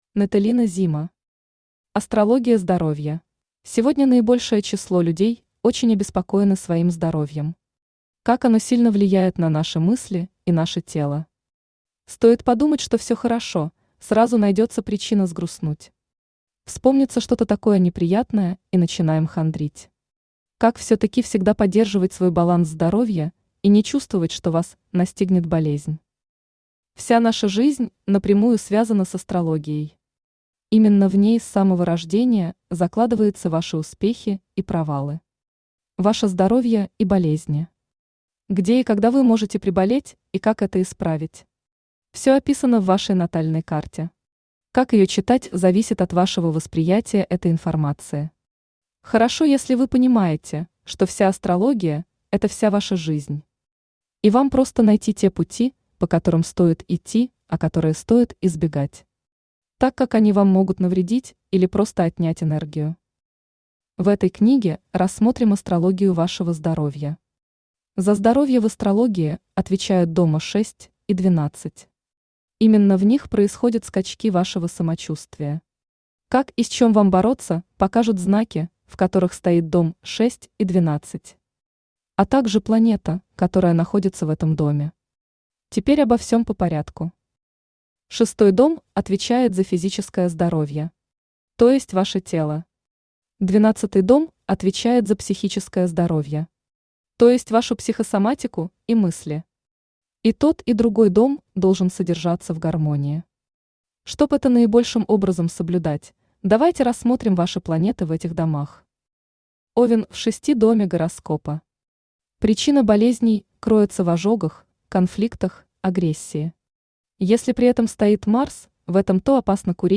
Аудиокнига Астрология здоровья | Библиотека аудиокниг
Aудиокнига Астрология здоровья Автор Natalina Zima Читает аудиокнигу Авточтец ЛитРес.